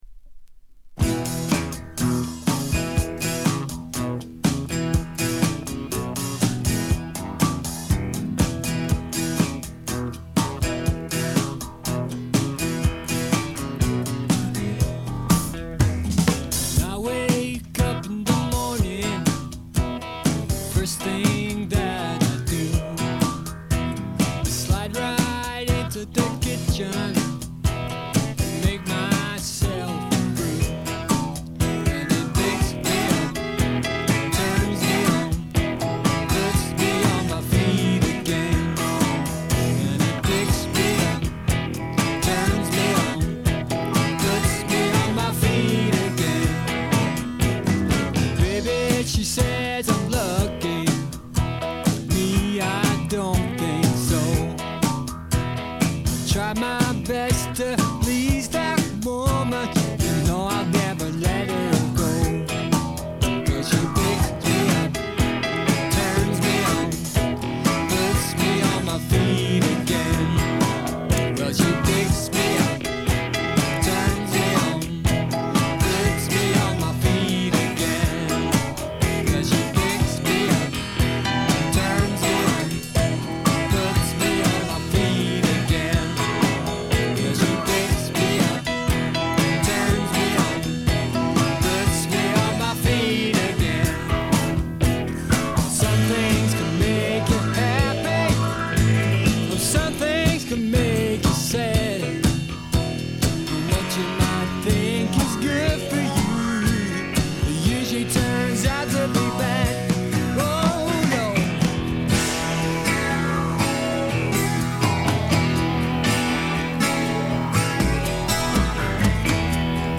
ホーム > レコード：英国 スワンプ
部分試聴ですが、微細なノイズ感のみ。
英国シンガーソングライター／スワンプの基本中の基本！
試聴曲は現品からの取り込み音源です。